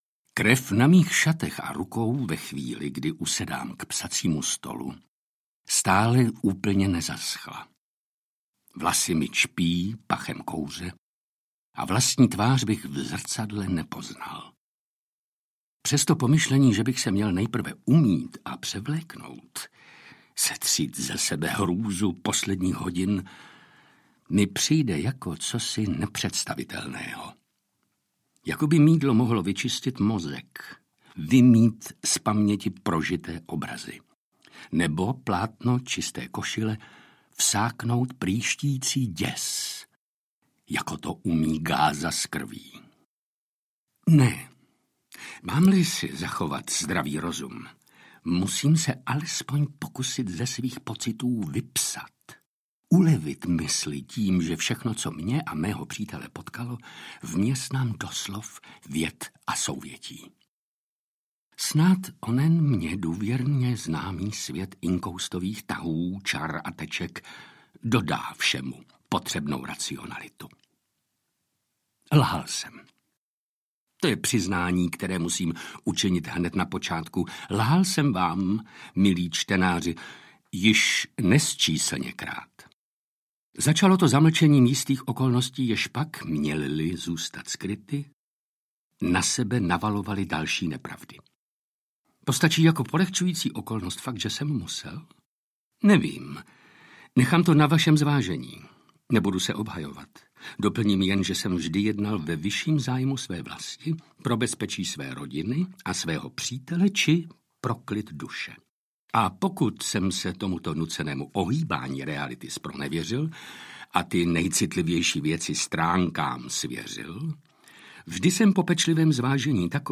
Sherlock Holmes - Hitlerův posel smrti audiokniha
Jan Přeučil (Sherlock Holmes) a Jan Vágner (dr. Watson) v hlavních rolích dobrodružného románu s postavou legendárního "Velkého detektiva", který napsal český spisovatel Petr Macek
Ukázka z knihy
• InterpretVladimír Brabec, Jan Přeučil, Jiří Dvořák, Jiřina Bohdalová, Jana Postlerová, Jan Vágner